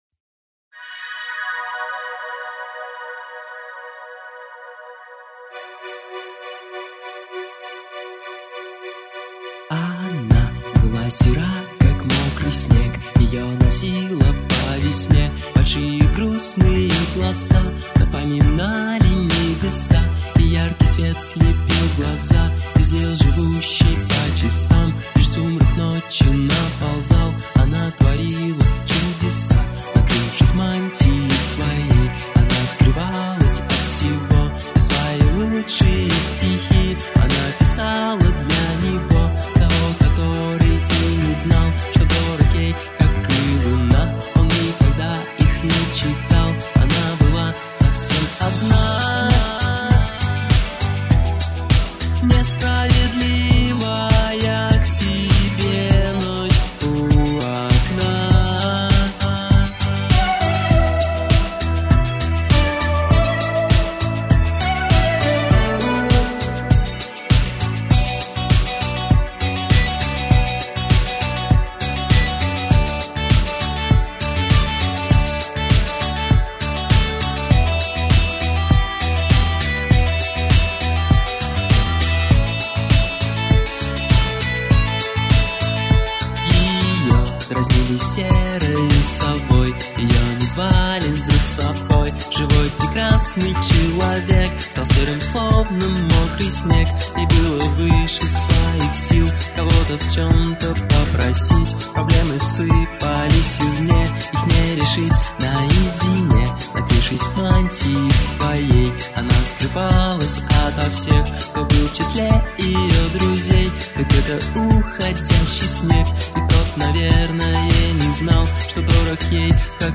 Песня.